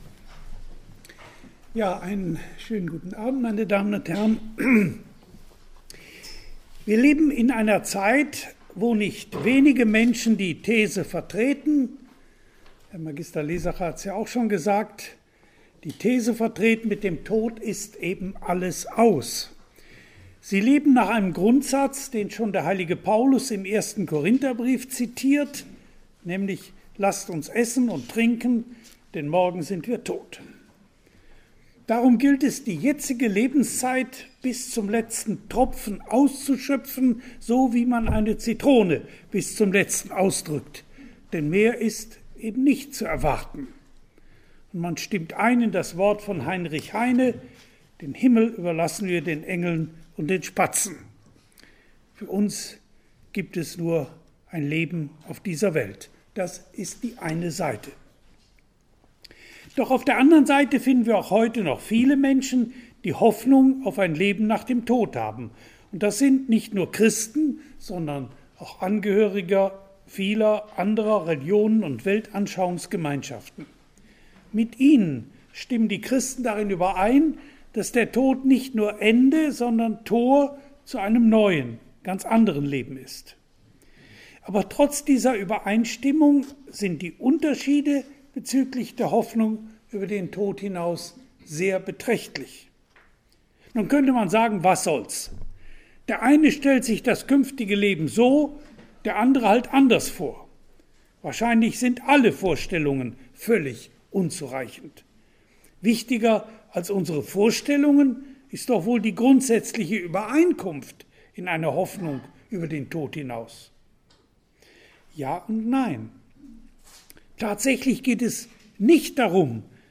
Der Vortrag von em. Univ.-Prof. Dr. Gisbert Greshake am 31. Jänner 2019 bei uns behandelte das Thema: Auferstehung der Toten oder Reinkarnation?